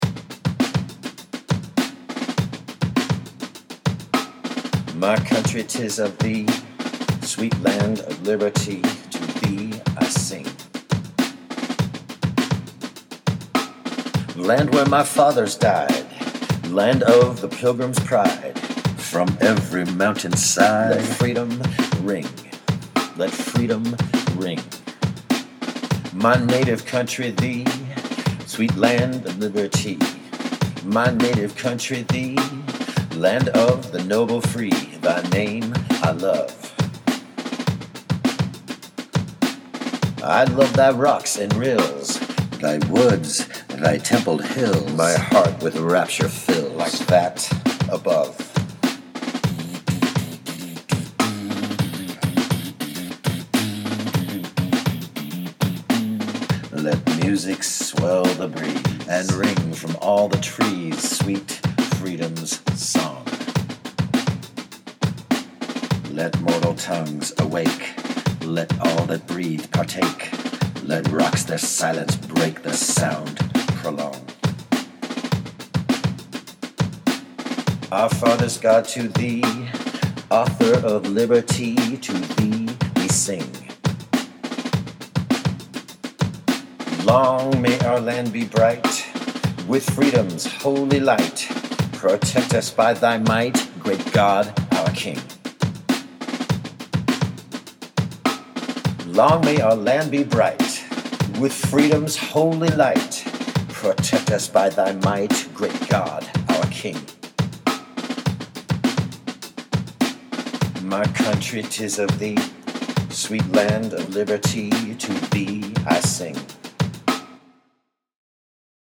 Spoken Word
My Country ÔTis of Thee.  I rapped the traditional lyrics of this classic American Patriotic song to a funky drum beat.